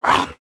khanat-sounds-sources/_stock/sound_library/animals/monsters/mnstr12.wav at main